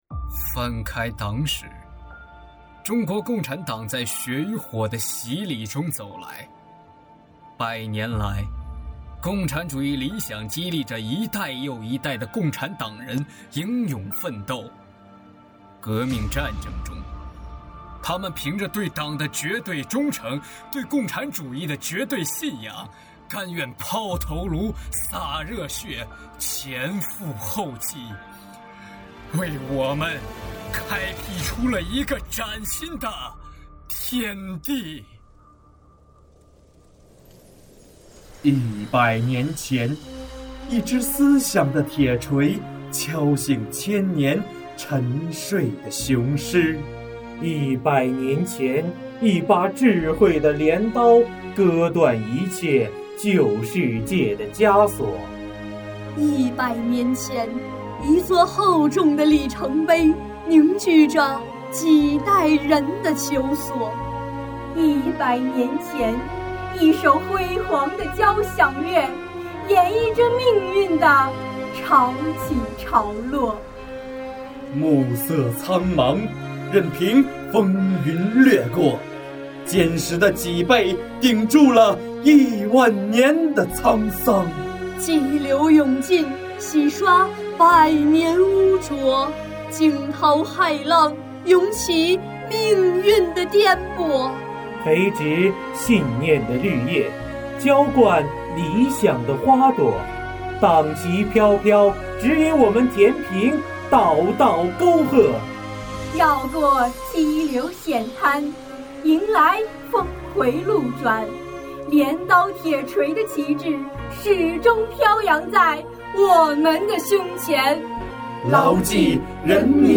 红色，是中国的颜色，它带着中国人血液红奋斗，坚毅的温度。诵读红色经典，传承百年荣光。